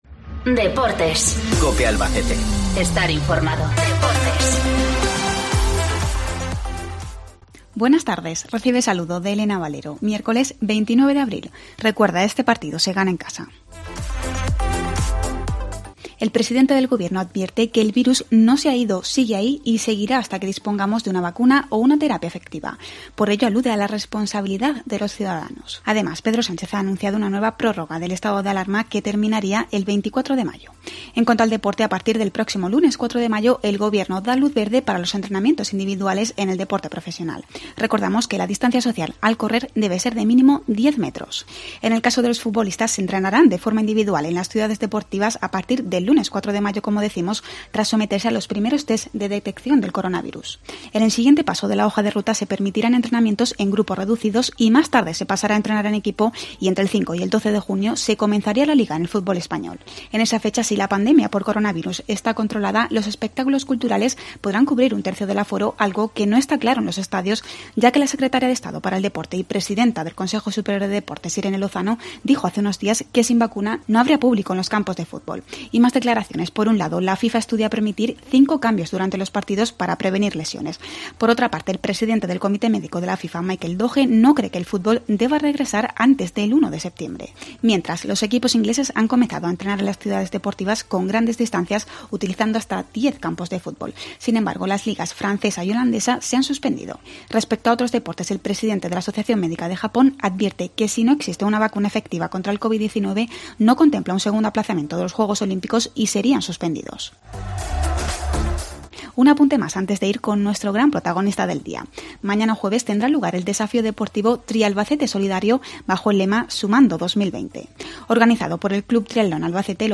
Albacete - Chinchilla - San Pedro Deportes Cope Albacete Luz verde a los entrenamientos individuales en el deporte profesional, a partir del 4 de mayo. Entrevista